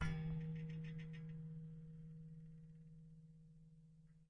乐器是由金属弹簧从一个大的卡拉巴什壳上延伸出来的；录音是用一对土工话筒和一些KK;接触话筒录制的，混合成立体声。 动态用pp（软）到ff（大）表示；名称表示记录的动作。
Tag: 声学的 金属制品 冲击 弹簧 拉伸